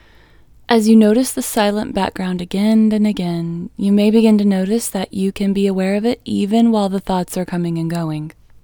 LOCATE IN English Female 20